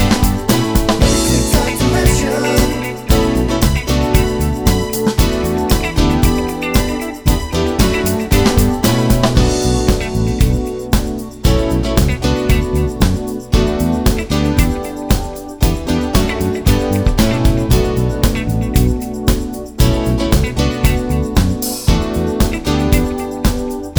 Live Pop (1980s)